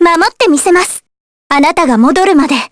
Jane_L-Vox_Victory_jp.wav